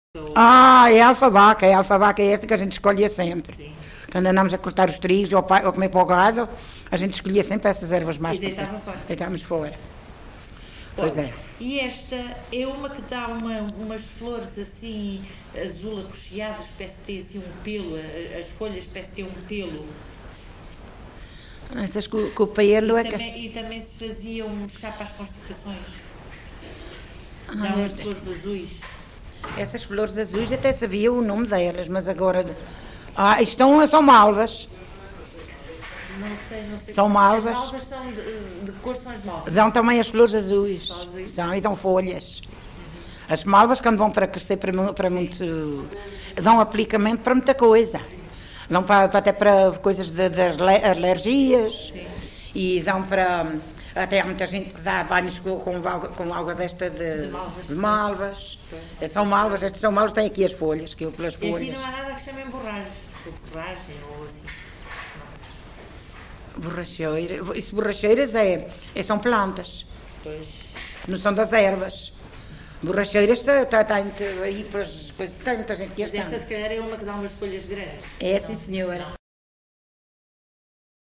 LocalidadeVila Pouca do Campo (Coimbra, Coimbra)